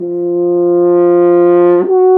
Index of /90_sSampleCDs/Roland L-CDX-03 Disk 2/BRS_F.Horn FX/BRS_Intervals
BRS F HRN 0O.wav